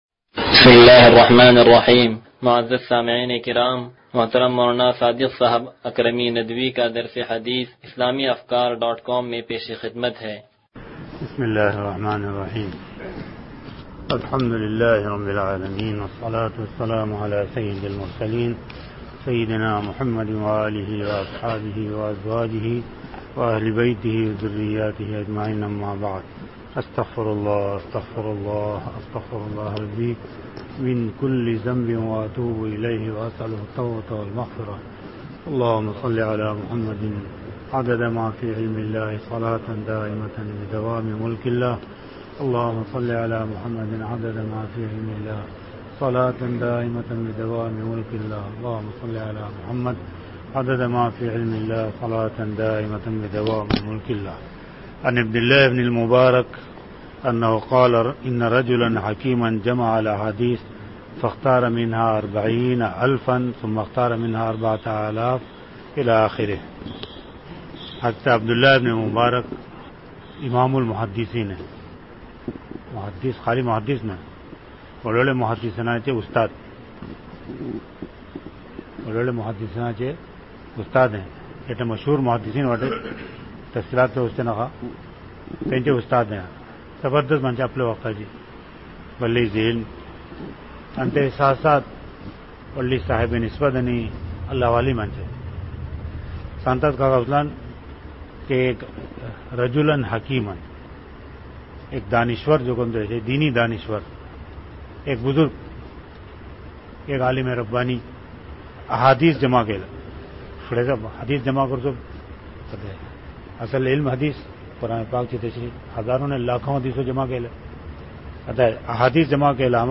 درس حدیث نمبر 0082
(تنظیم مسجد)